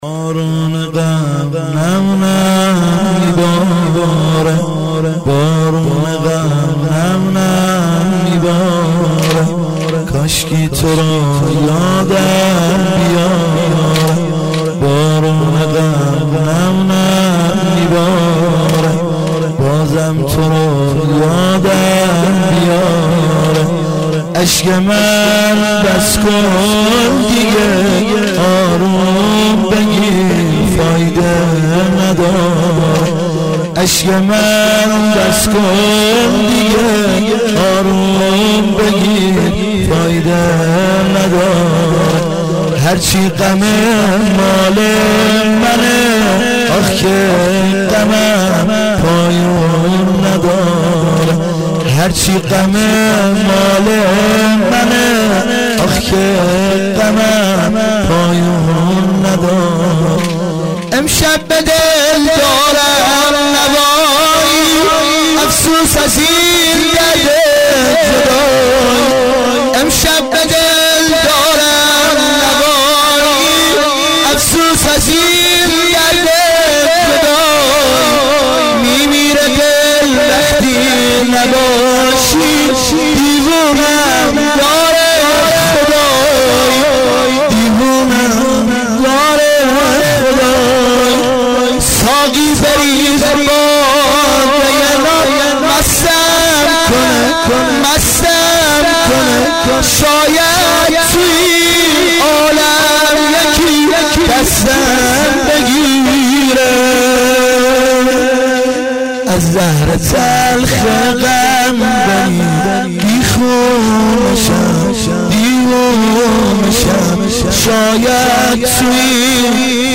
هیات العباس(ع)